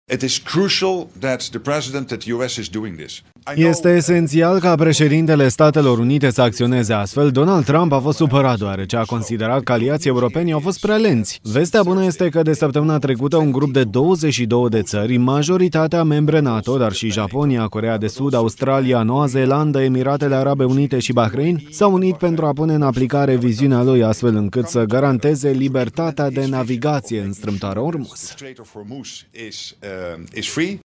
Președintele american Donald Trump a dat un ultimatum Teheranului – dacă nu deschide complet Strâmtoarea Ormuz în 48 de ore, atunci vor fi lovite centrale electrice iraniene. O astfel de măsură este necesară, a declarat liderul NATO, Mark Rutte, la postul de televiziune Dot Republic Media.
23mar-13-Rutte-ultimatumul-este-decizia-potrivita-TRADUS.mp3